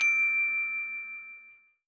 SpeechCheck-Pass.wav